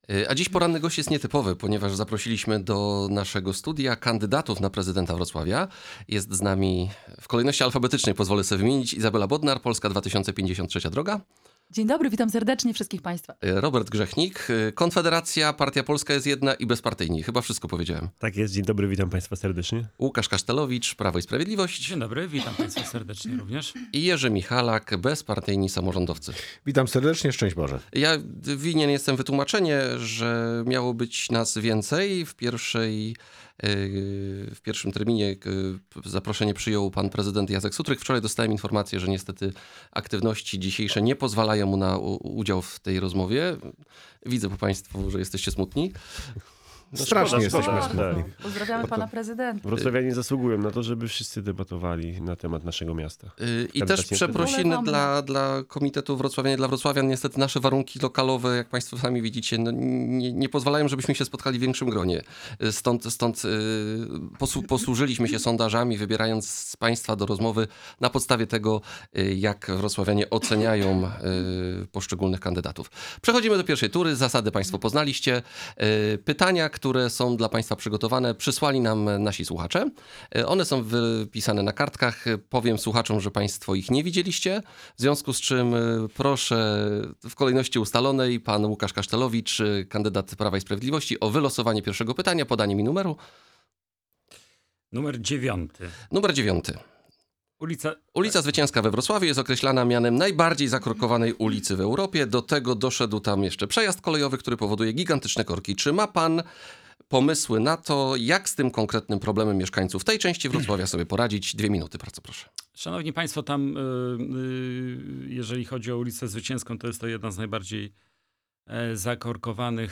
Ostatnia przed ciszą wyborczą debata prezydencka - Radio Rodzina
Kandydaci na urząd Prezydenta miasta Wrocławia rozmawiali dziś w studiu Radia Rodzina, odpowiadając na pytania naszych słuchaczy oraz dyskutując między sobą na temat różnych pomysłów na stolicę Dolnego Śląska.
Debata-prezydencka.mp3